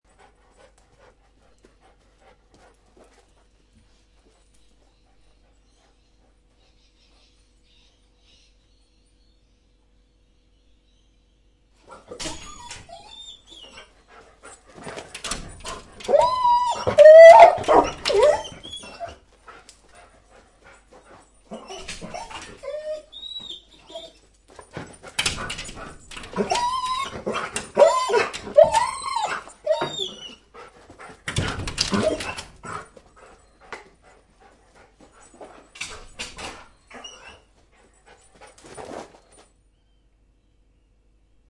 Dog Whining And Jumping On Glass Bouton sonore